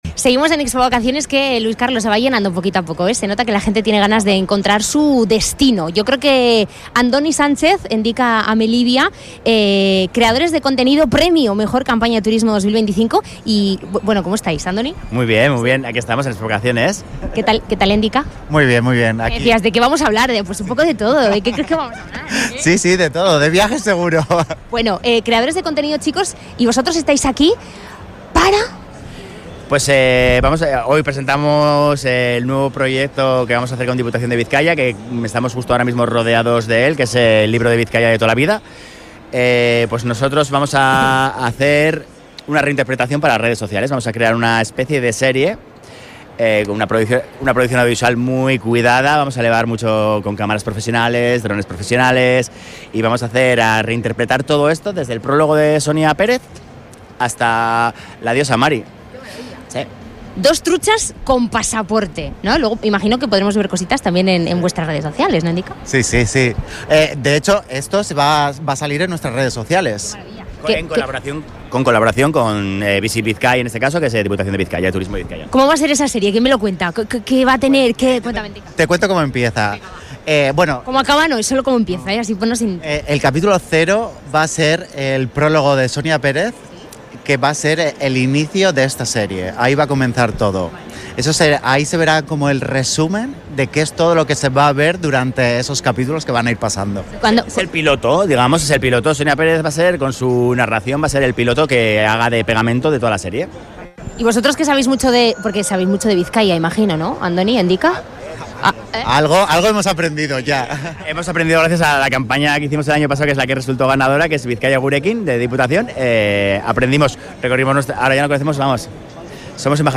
en la Feria de Expovacaciones
Los creadores de contenido